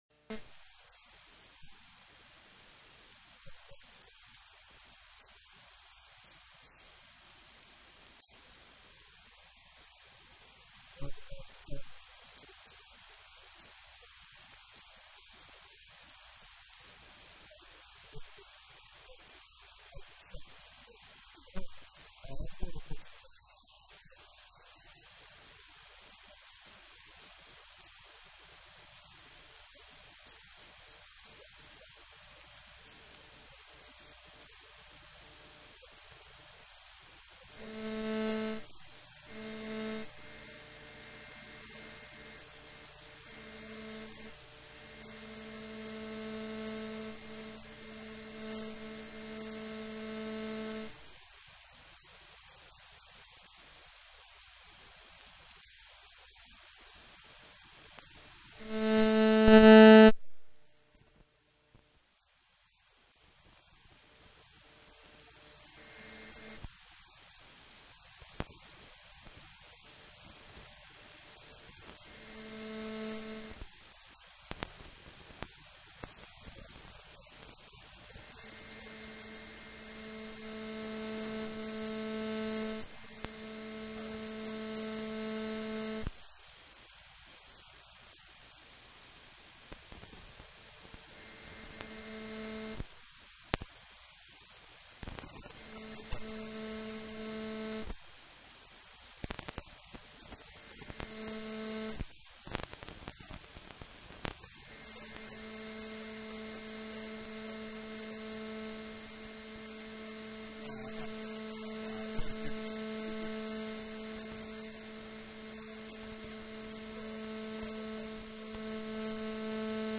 one on one with MLB stars of the game